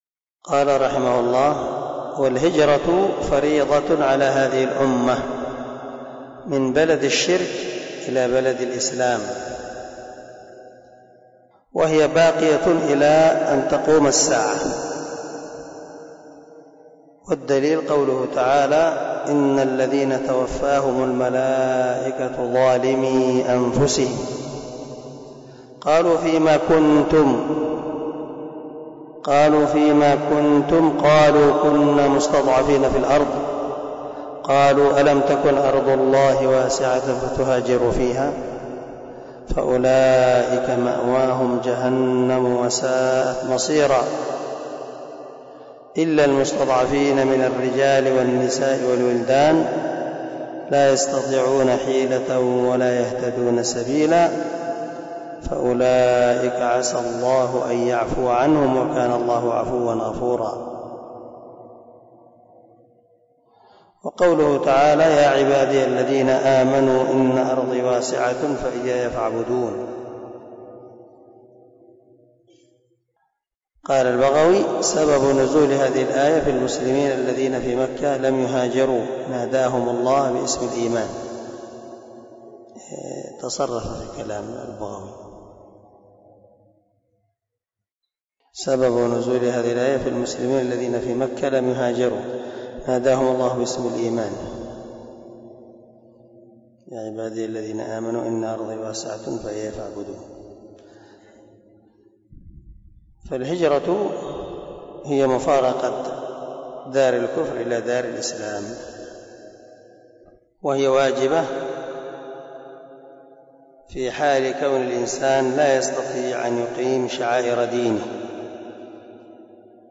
🔊 الدرس 34 من شرح الأصول الثلاثة